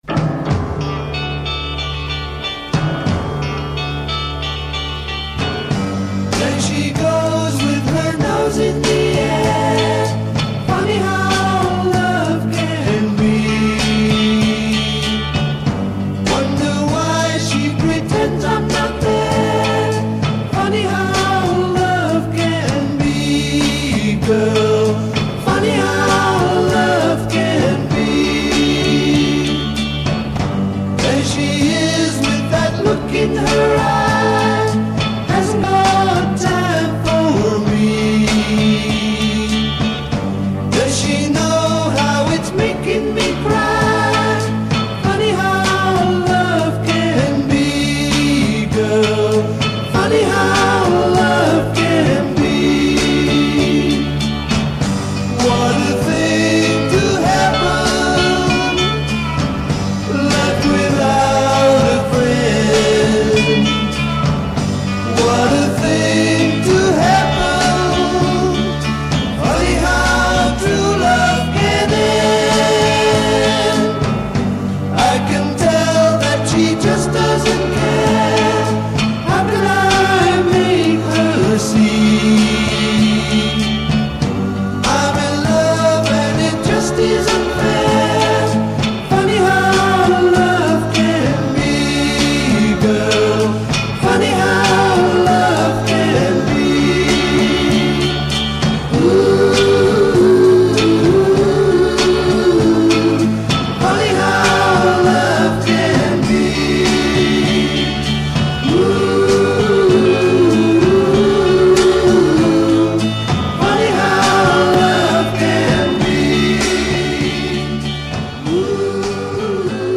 vocals
bass
keyboards
guitar
drums
Coda : 16 Repeat hook and fade b